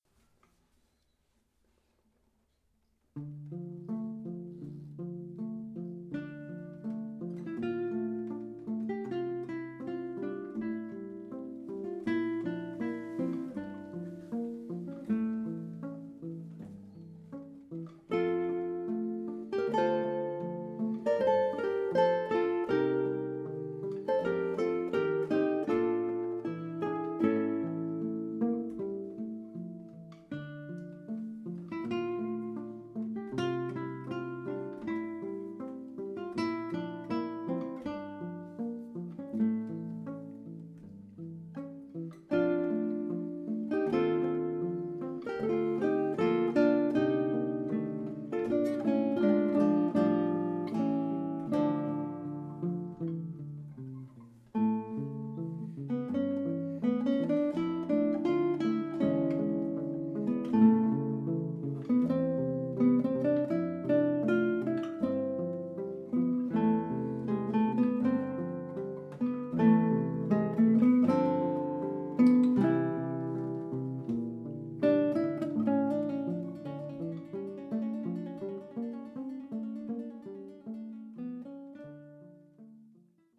Die Hörproben des Quartetts sind ungeschnitten und nicht nachbearbeitet, daher ist nicht mit Aufnahmen in Studio-Qualität zu rechnen.
Die Stücke sind aus verschiedenen Epochen und Stilen ausgewählt, um die Vielseitigkeit des fantastischen Instruments Konzertgitarre zu zeigen.